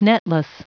Prononciation du mot netless en anglais (fichier audio)
Prononciation du mot : netless